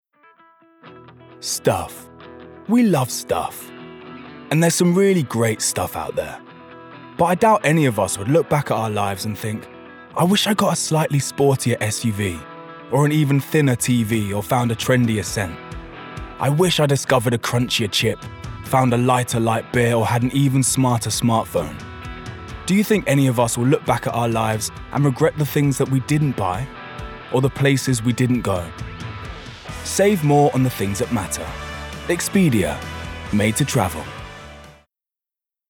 London
Male
Cool
Fresh
Smooth